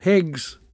[hEHggs]